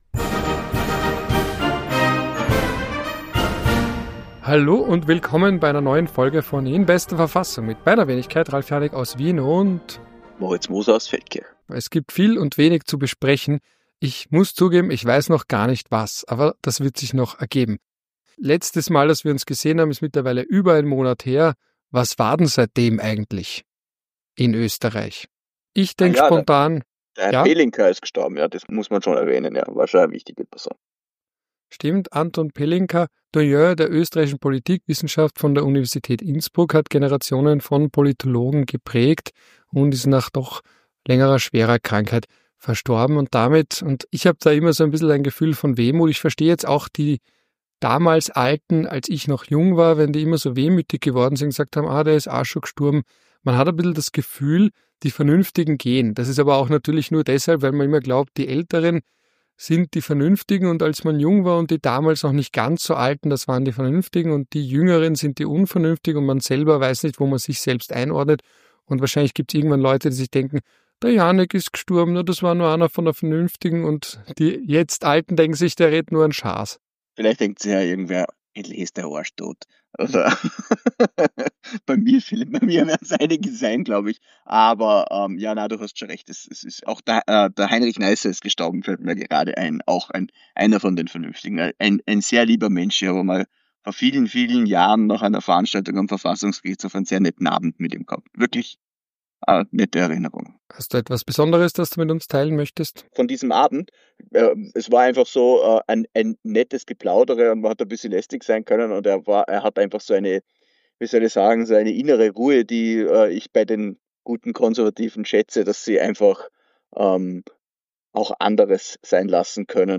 Ein Politologe und ein Jurist sprechen über Politik und Recht (man weiß nicht immer, wer wer ist).